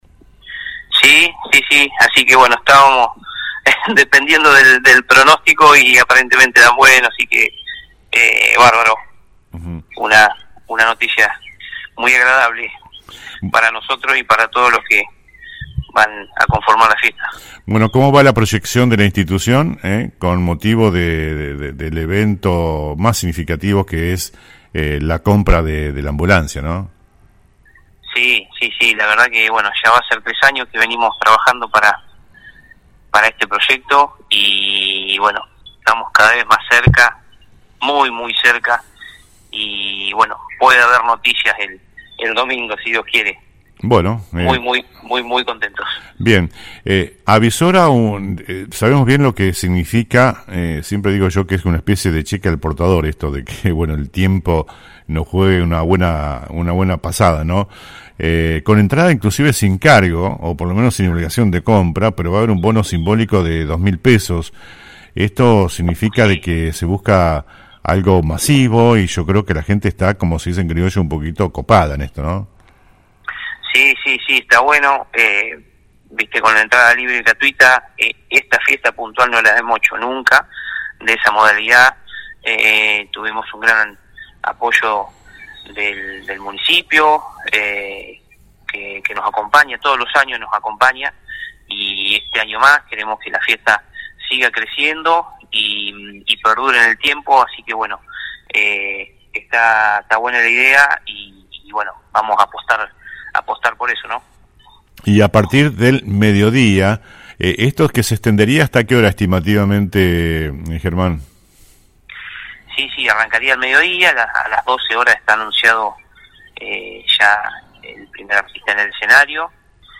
En diálogo con el programa «El Periodístico»